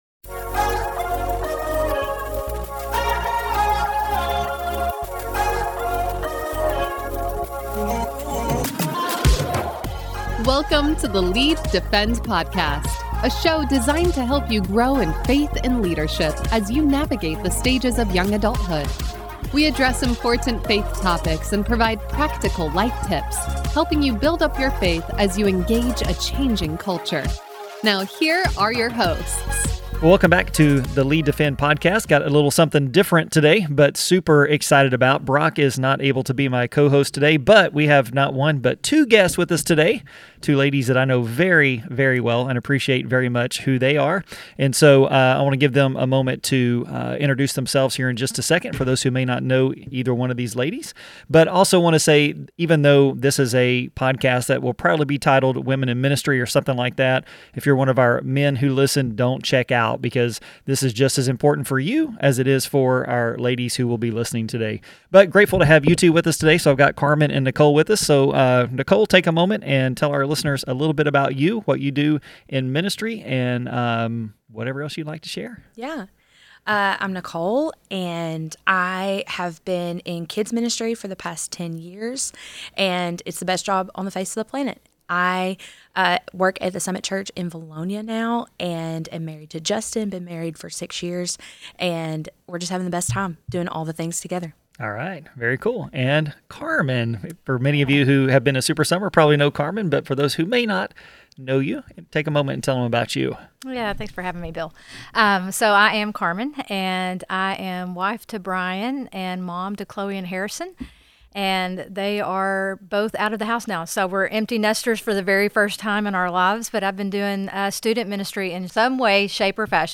A Conversation on Women in Ministry